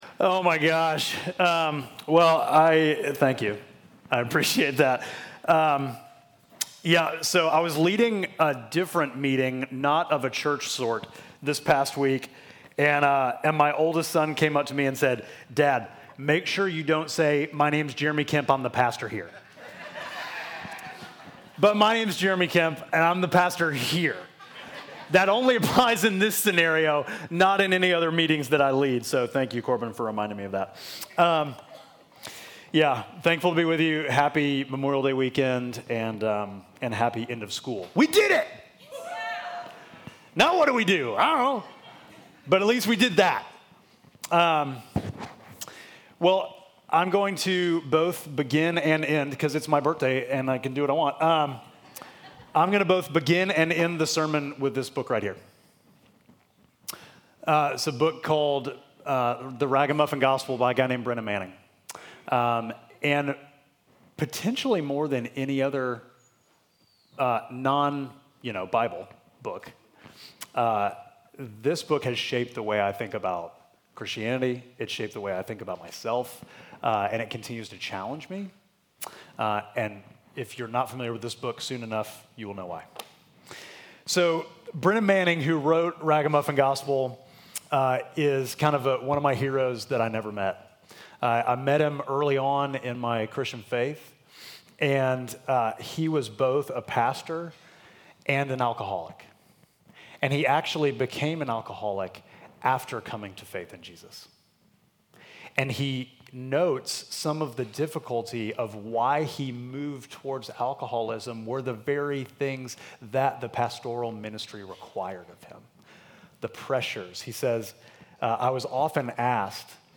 Midtown Fellowship Crieve Hall Sermons Grace Changes Everything May 25 2025 | 00:42:20 1x 00:00 / 00:42:20 Subscribe Share Apple Podcasts Spotify Overcast RSS Feed Share Link Embed